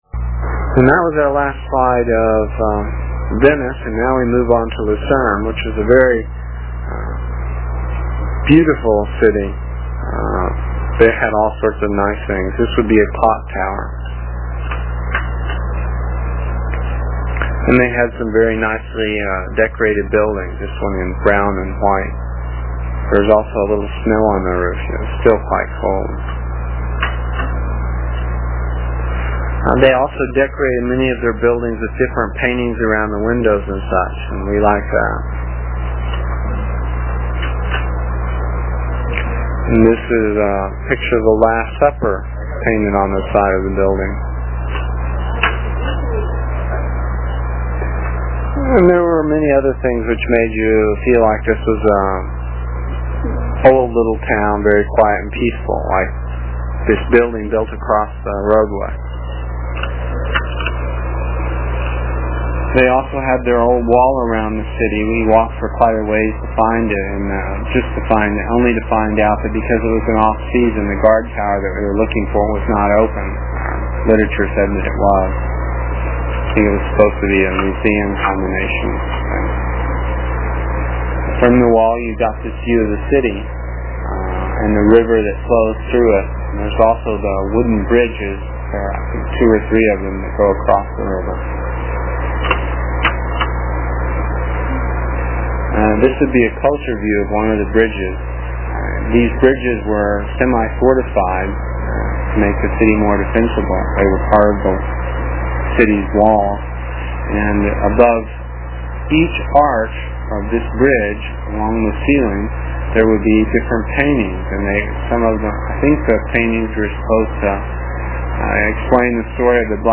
It is from the cassette tapes we made almost thirty years ago. I was pretty long winded (no rehearsals or editting and tapes were cheap) and the section for this page is about five minutes and will take a couple of minutes to download with a dial up connection.